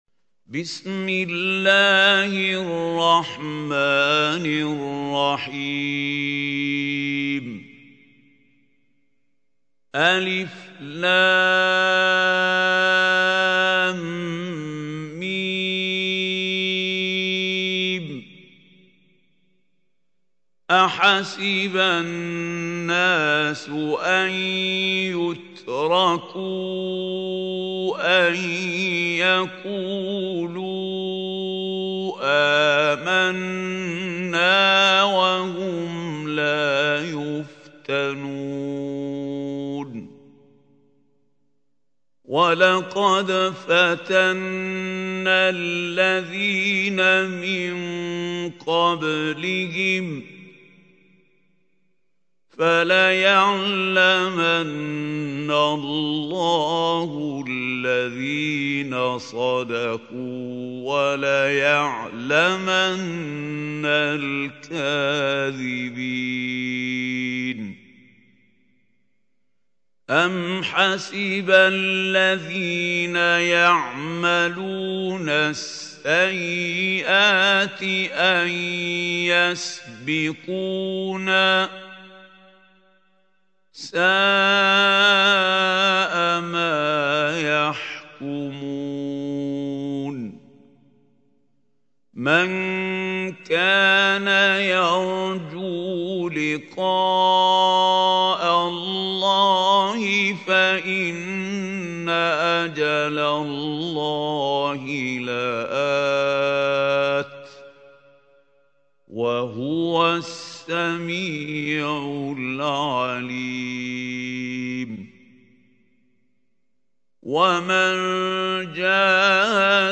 سورة العنكبوت | القارئ محمود خليل الحصري